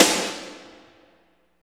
55.04 SNR.wav